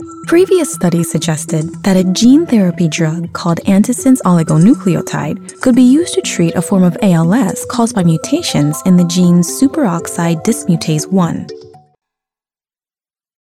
Authentic, friendly, female voice talent with quick turnaround and superb customer service
Medical Narration
Medical_Narration.mp3